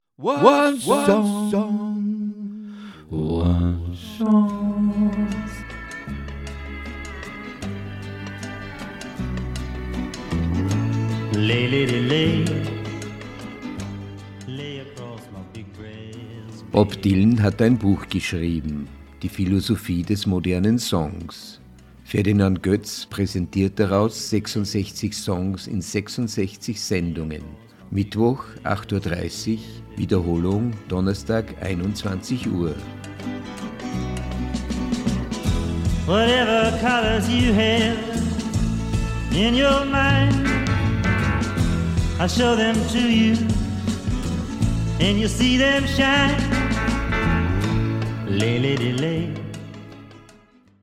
Sendungstrailer